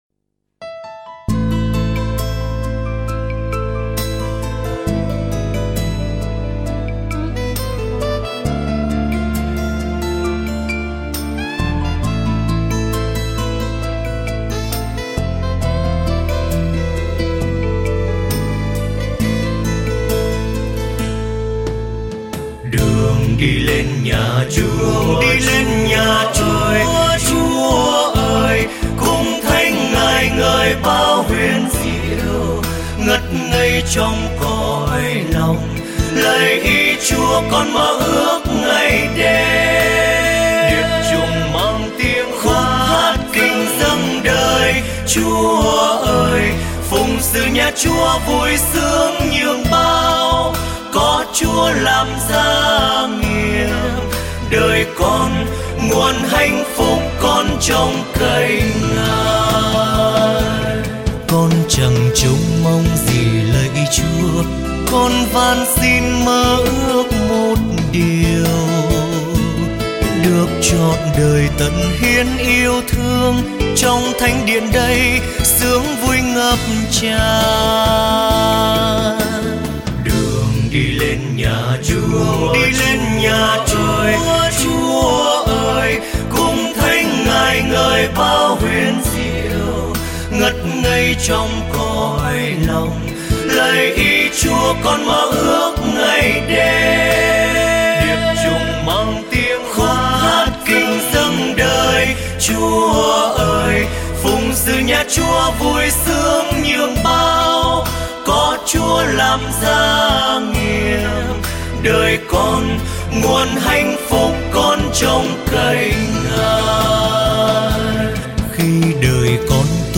Nghe nhạc thánh ca. Bài hát được phát từ Website